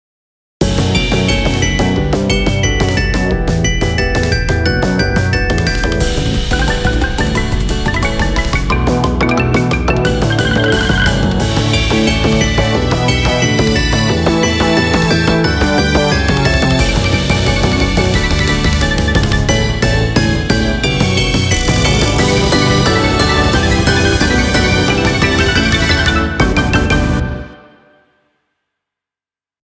どんちゃんわいわい、無法地帯。
inst